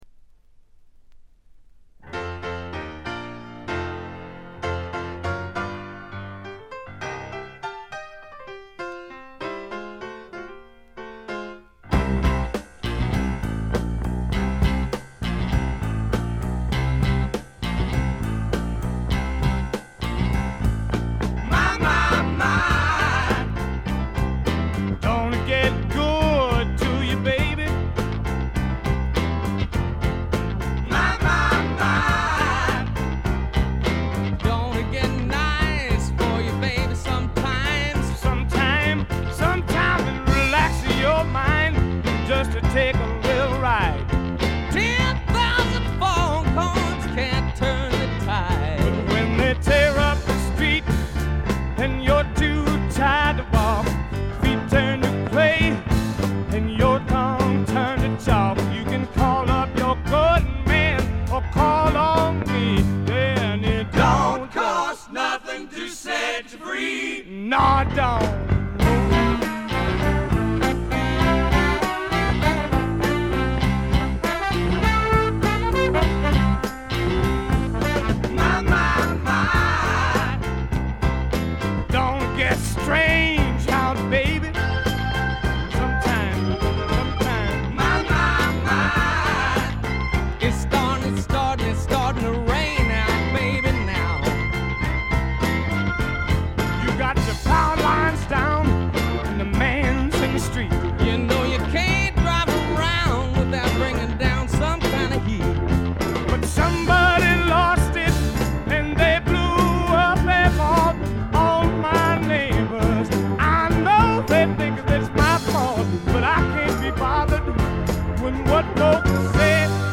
ほとんどノイズ感無し。
ブルース･ロックとスワンプ・ロックを混ぜ合わせて固く絞ったような最高にグルーヴィでヒップなアルバムです。
試聴曲は現品からの取り込み音源です。